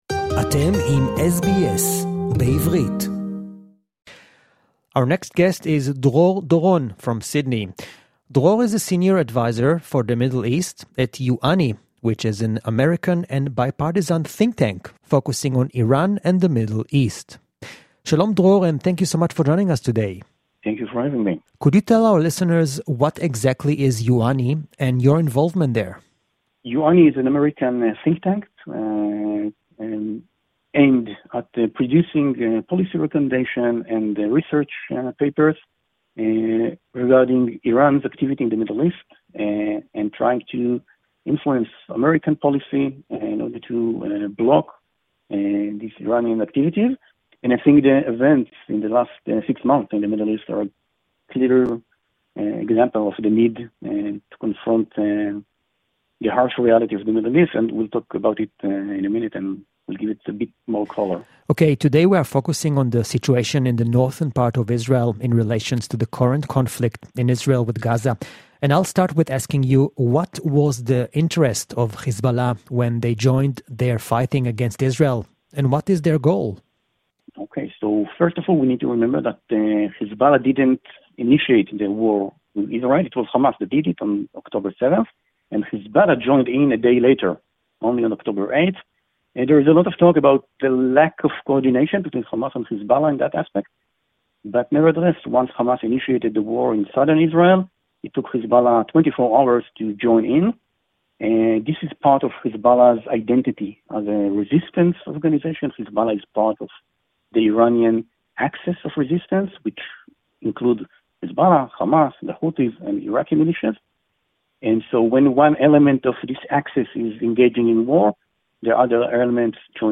(English Interview)